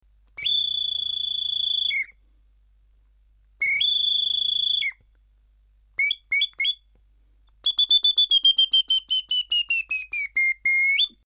小さな鳥笛｜手づくり楽器 ～ 音 遊 具 ～